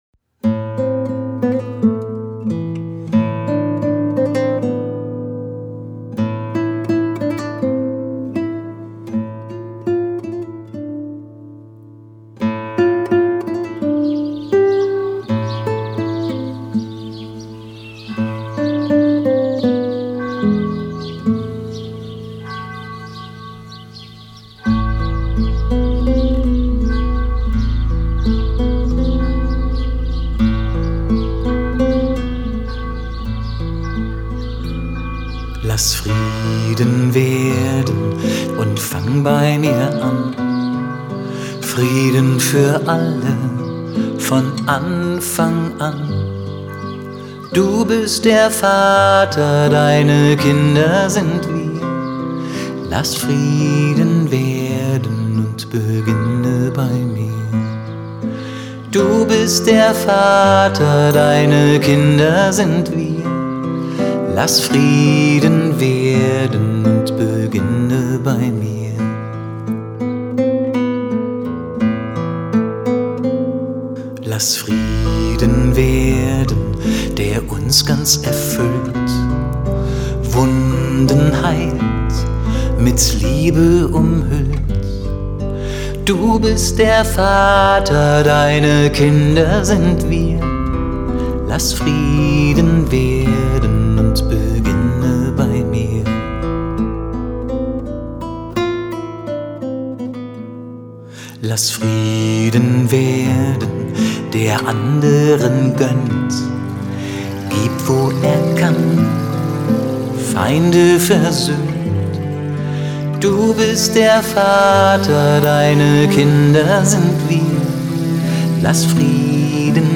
Gitarre und Gesang
deutsche Version